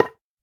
Minecraft Version Minecraft Version snapshot Latest Release | Latest Snapshot snapshot / assets / minecraft / sounds / block / bone_block / break4.ogg Compare With Compare With Latest Release | Latest Snapshot
break4.ogg